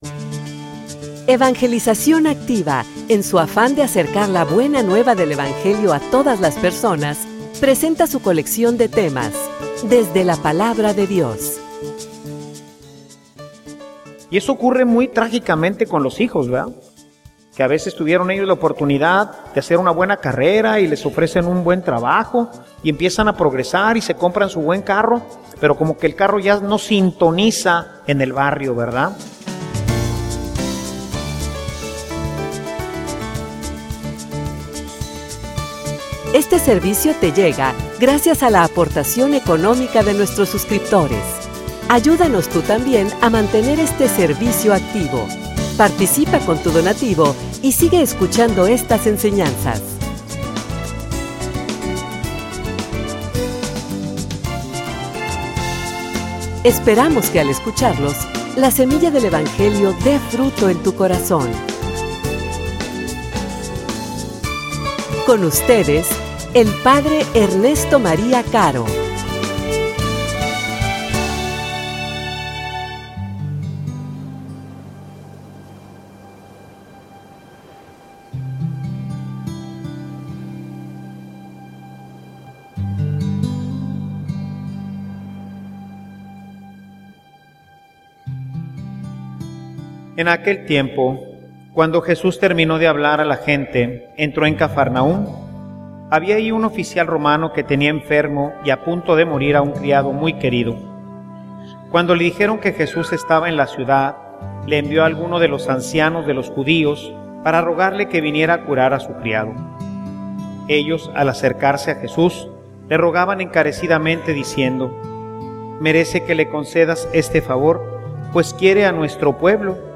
homilia_Aprendiendo_de_un_centurion.mp3